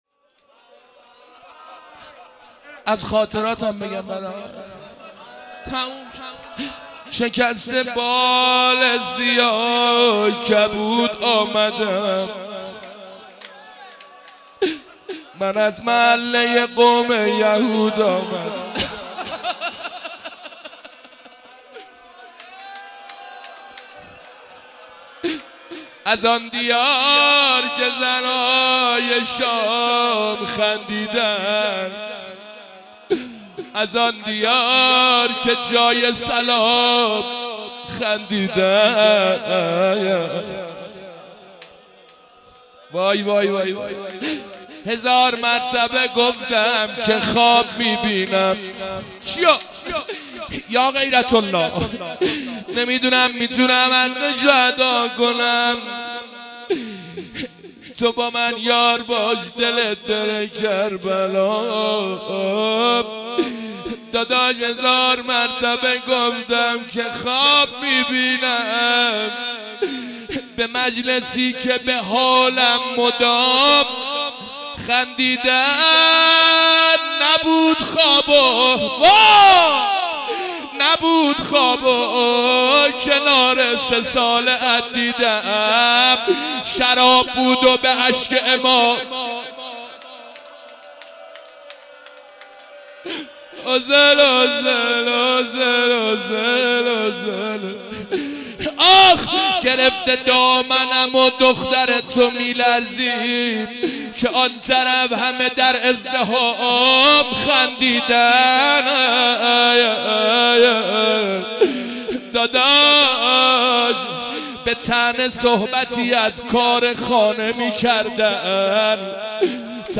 مناجات امام زمان